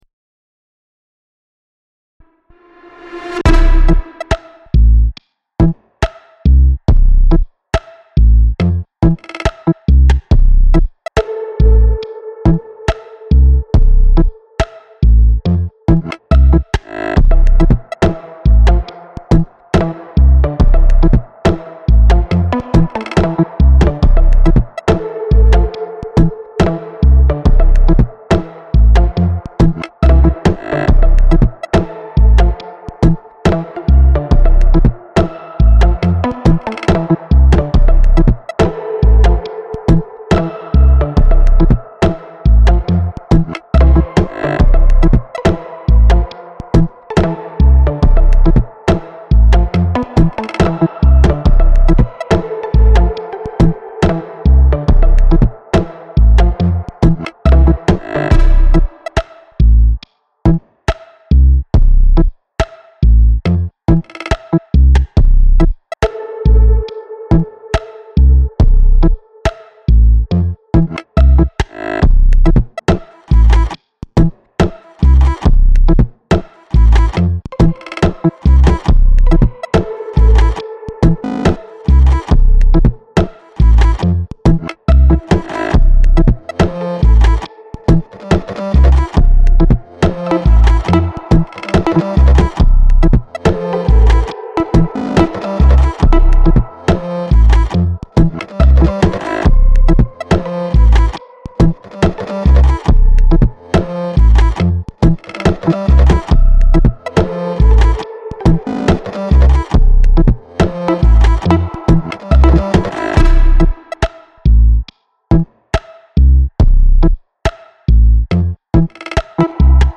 uhh das low end ist wirklich nice! der bassiert mir grad die füße!
Ach so, hier mal in Stereo ( sorry ) mit minimalen Veränderungen. Es ist immernoch ein 4bar-Loop, aber eben in dieser fantastischen Räumlichkeit, wie nur Stereo sie bietet.
Es dringt aber durch jeden einzelnen Sound durch finde ich.
Ich wollte die trost- und hoffnungslose Eintönigkeit der Tage zum Ausdruck bringen!